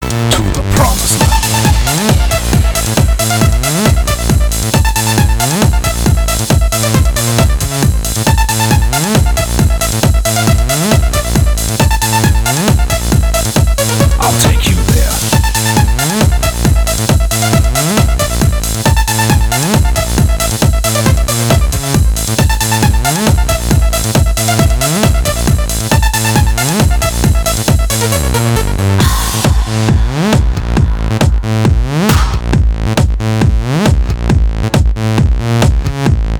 громкие
энергичные
electro house
acid house
Рингтон в стиле электро.